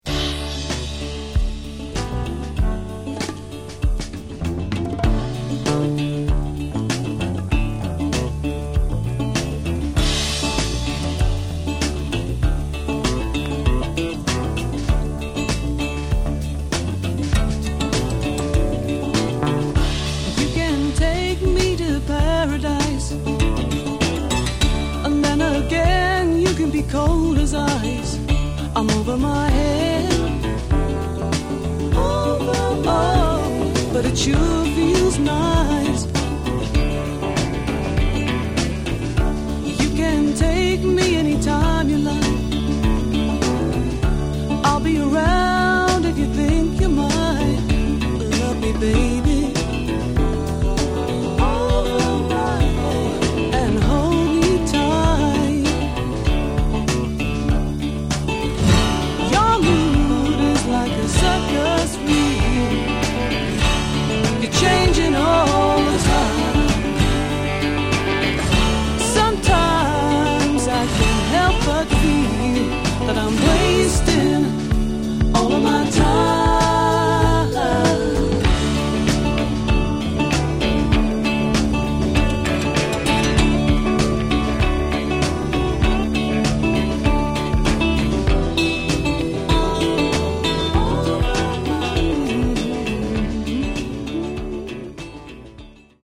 Genre: Folk Rock